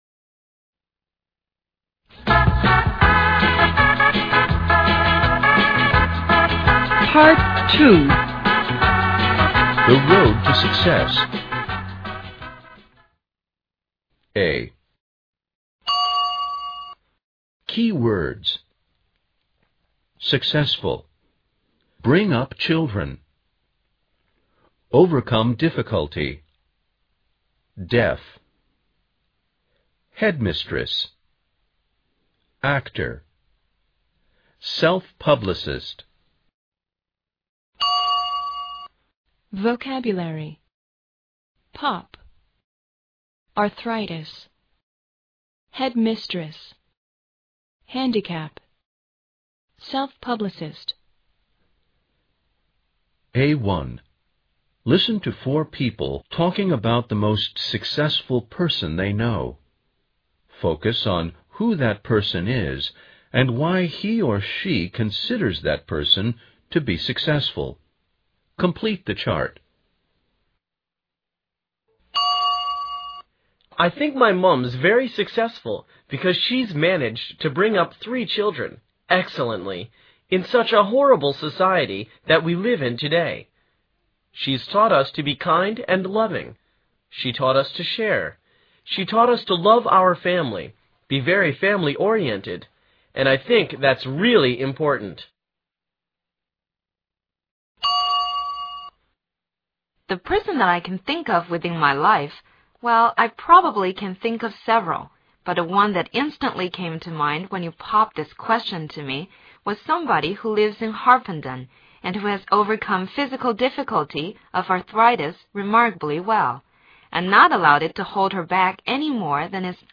A1. Listen to four people taking about the most successful person they know. Focus on who that person is and why he or she considers that person to be successful.